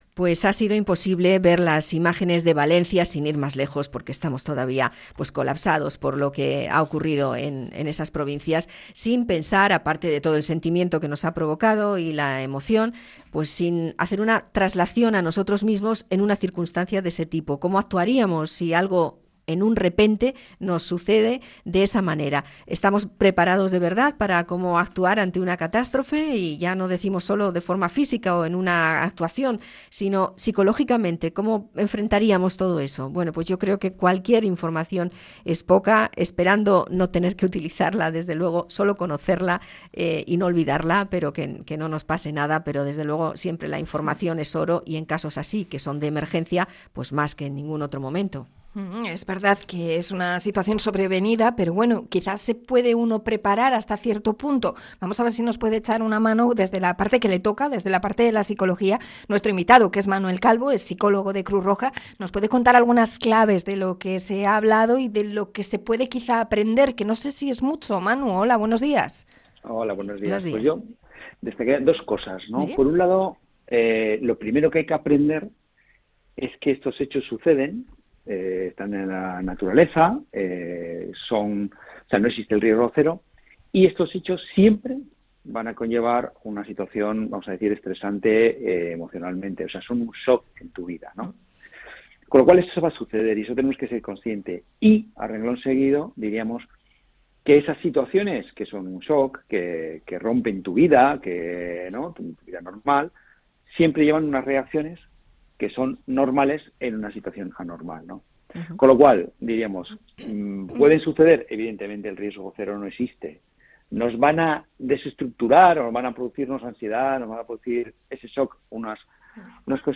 Entrevista a Cruz Roja Bizkaia sobre su charla sobre cómo actuar en una catástrofe